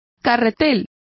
Complete with pronunciation of the translation of reel.